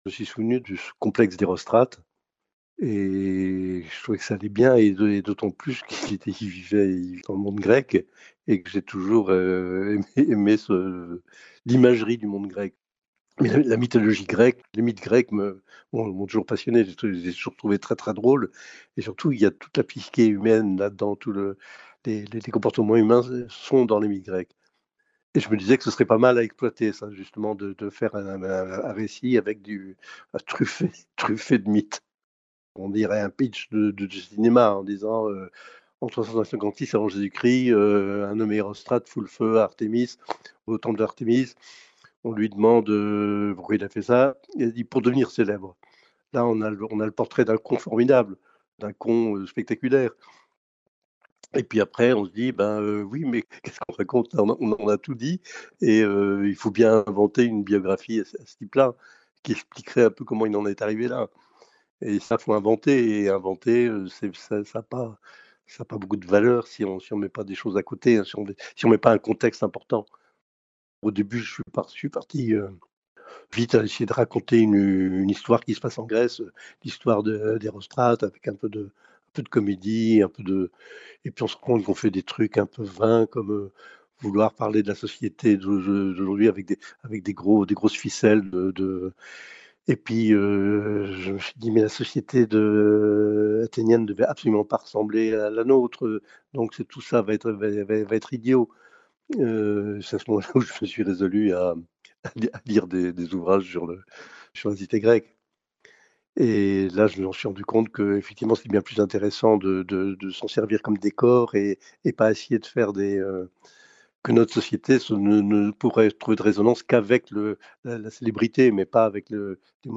entretien avec Martin Veyron